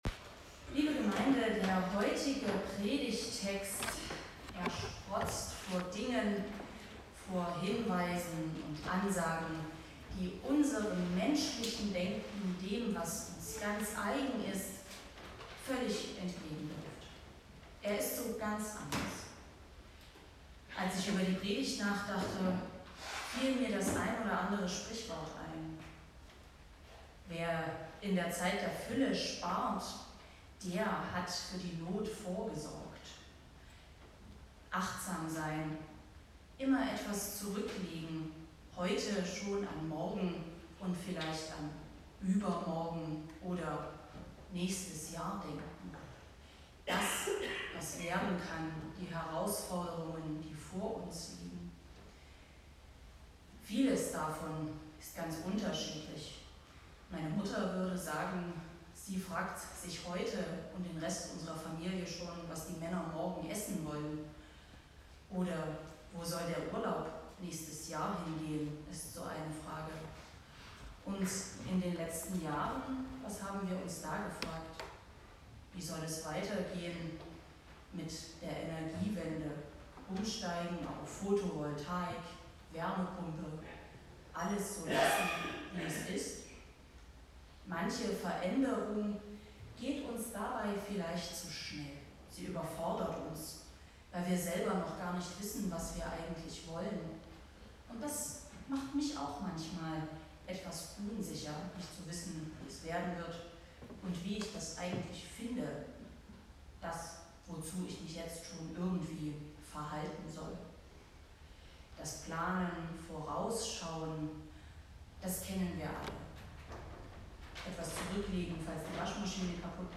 Passage: Matth. 6,25-34 Gottesdienstart: Predigtgottesdienst Wildenau « Der Heilige Geist ist die Triebkraft Gottes Wovor haben Sie Angst?